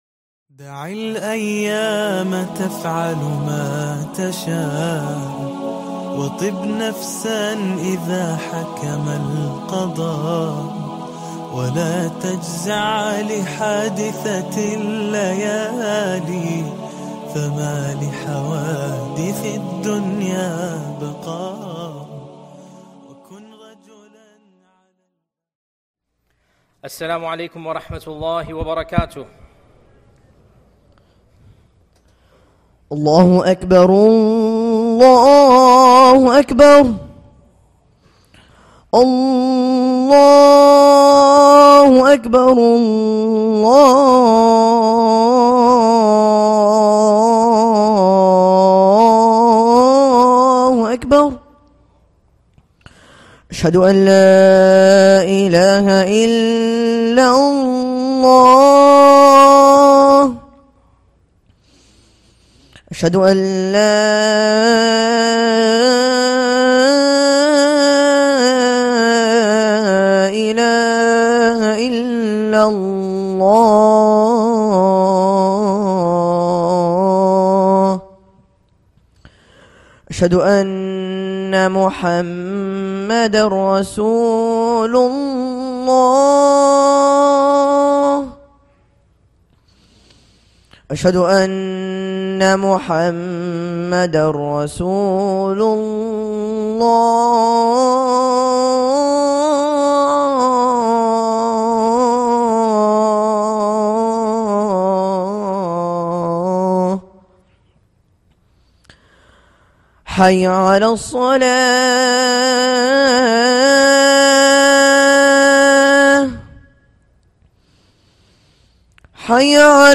A Khuṭbah For Inquisitive Minds